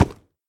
Sound / Minecraft / mob / horse / wood4.ogg
wood4.ogg